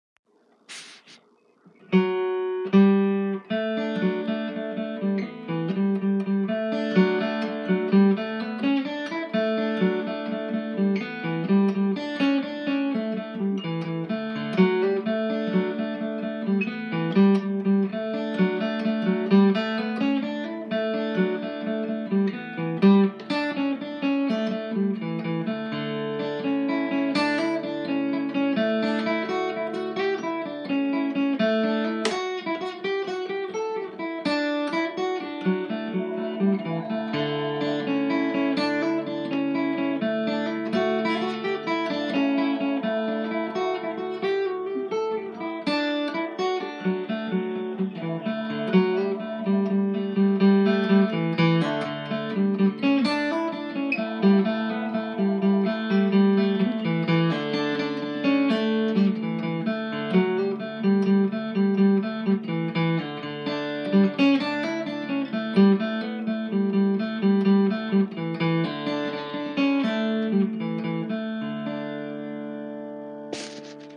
Key: D
Form:Jig
M: 6/8